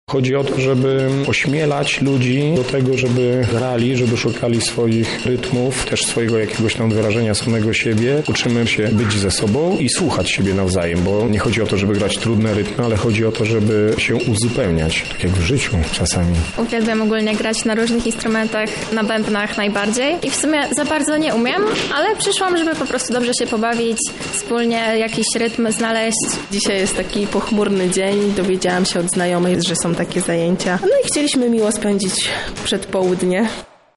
Plastikowe wiaderko, puste butelki, a nawet stare krzesło mogą posłużyć do tworzenia muzyki.
Uczestnicy przynosili ze sobą zużyte opakowania, dzięki którym powstały rytmiczne dźwięki.
muzyka recyklingowa
muzyka-recyklingowa.mp3